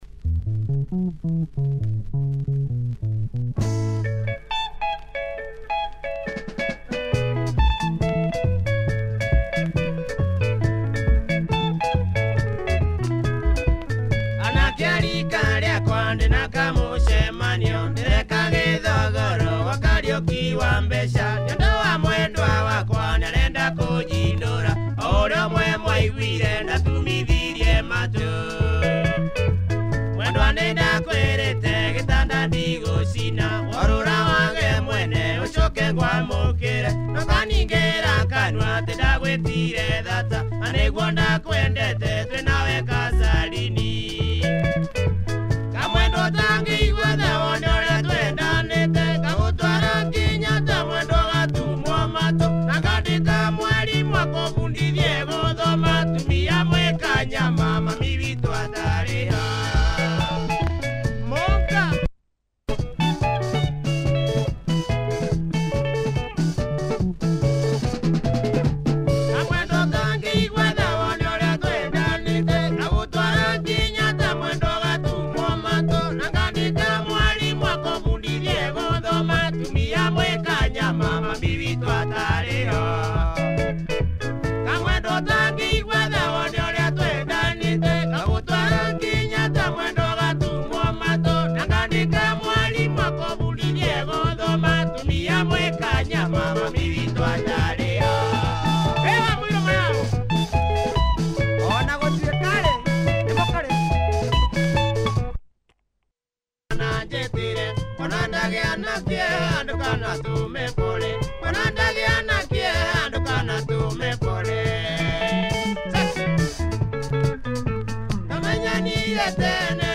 Nice Kikuyu benga,, check audio for both sides. https